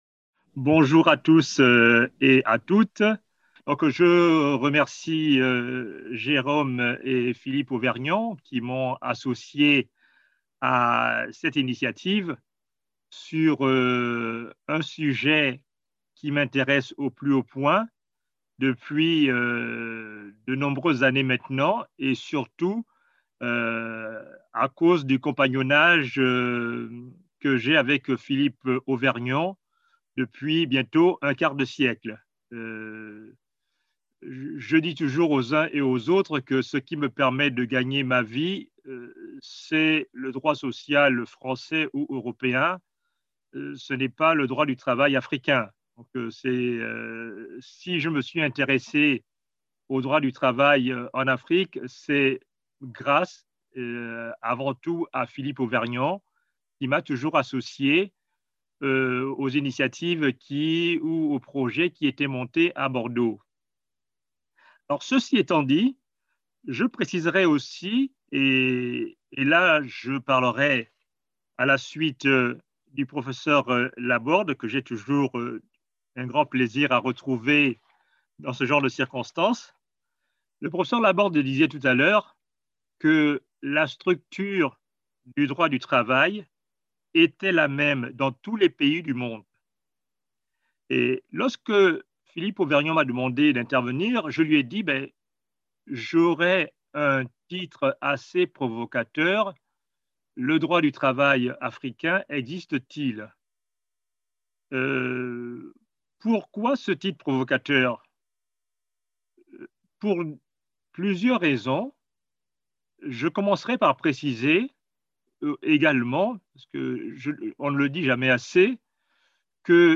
Cette présentation a été donnée dans le cadre du séminaire mis en place par le groupe de travail LabAfrica, porté par plusieurs centres de recherche bordelais (COMPTRASEC, GRETHA et LAM).